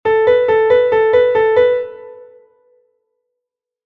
2/4, Do e Mi todo semicorcheas intercaladas